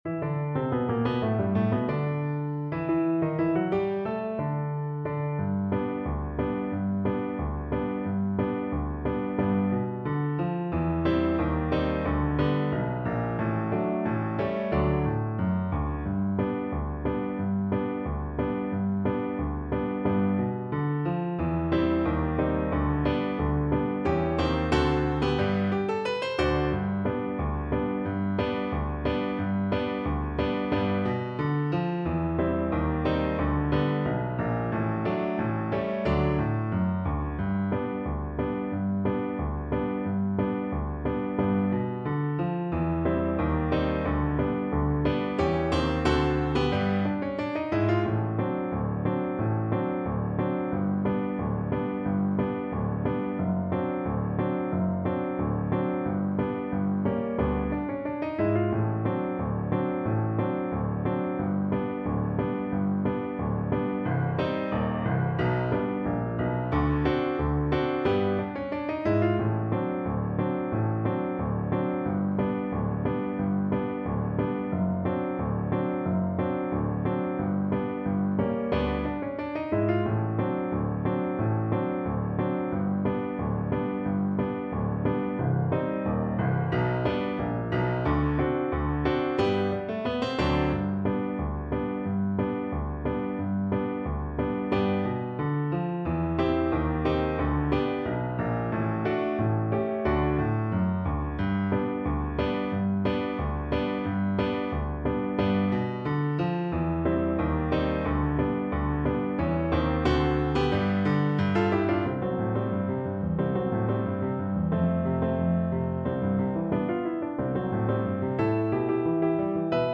Viola
G major (Sounding Pitch) (View more G major Music for Viola )
Slow march tempo Slow March tempo. = 90
2/4 (View more 2/4 Music)
Jazz (View more Jazz Viola Music)